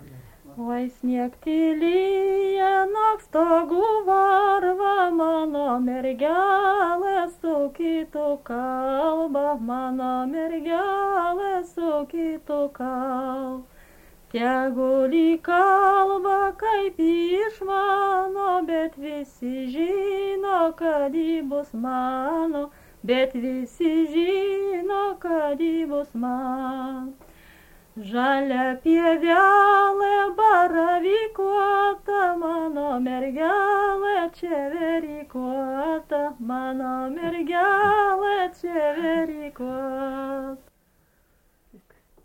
Tipas daina Erdvinė aprėptis Nemunaitis
Atlikimo pubūdis vokalinis